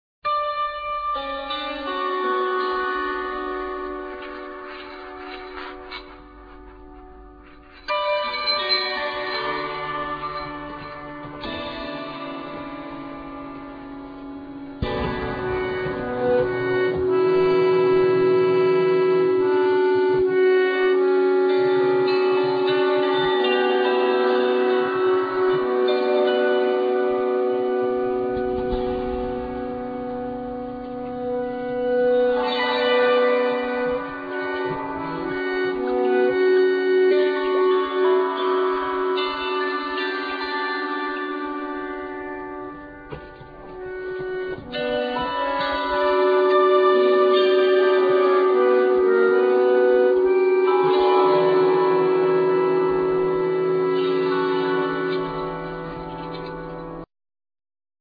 Vocals,Viola
Voclas,Guitar,Lute,Duduk,Harmonica,Percussions,etc
Viola da ganba
Percussions